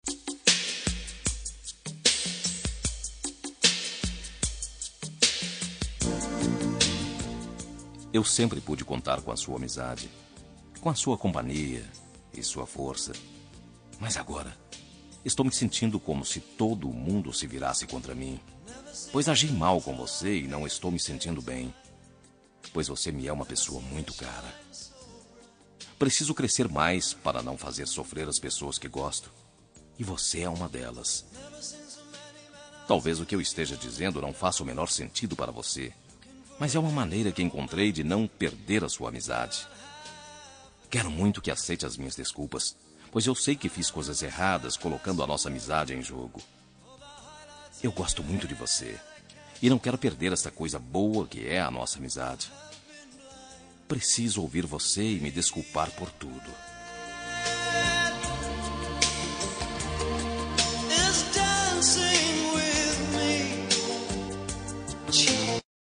Telemensagem Desculpas – Voz Masculina – Cód: 8069